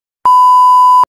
buzz.mp3